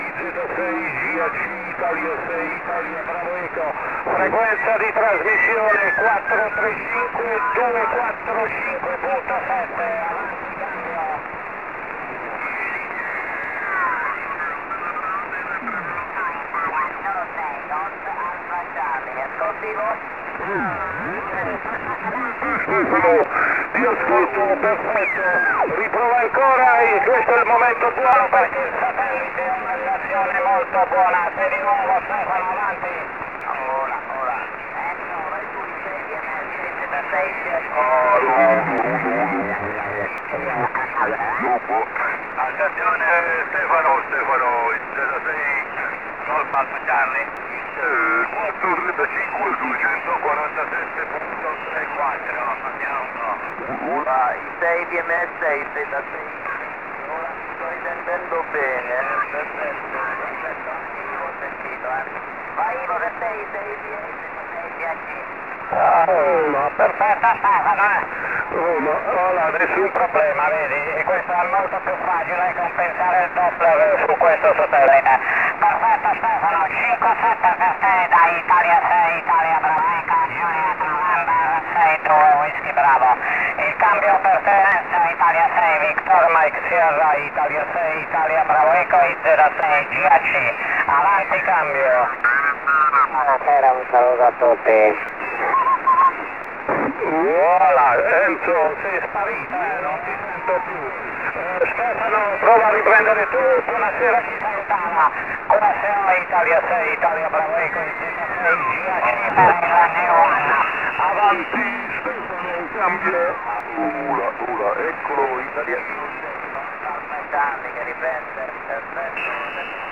Registrazioni AUDIO MP3 del nostro primo qso via AO-51 (Oscar 51) , SO-50, FO-29, VO-52
vo52.mp3